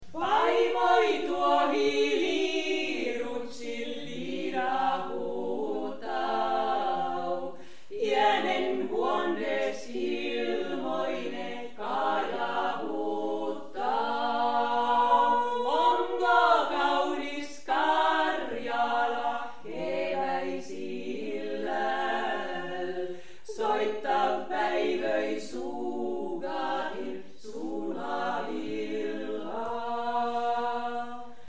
Kuhmon Kalevalakylä 2004
mies ja nainen